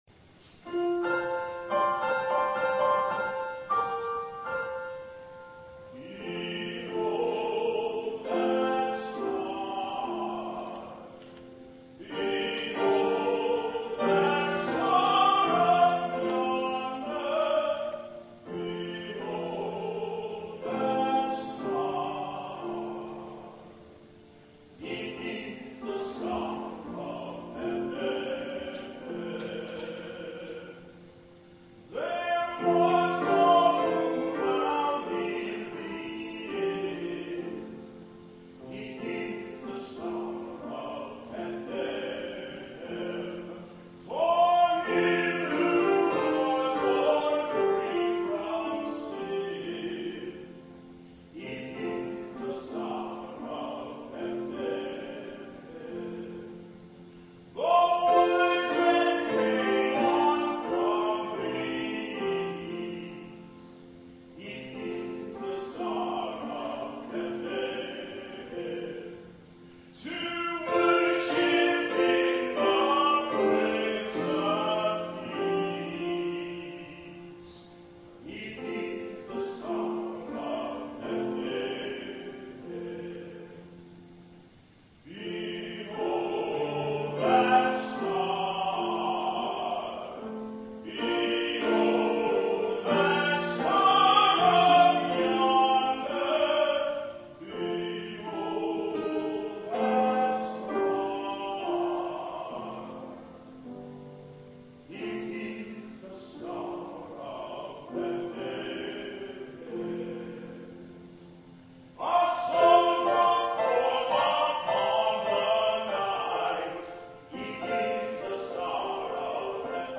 tenor sings his solo "Behold That Star" arr. by H.T. Burleigh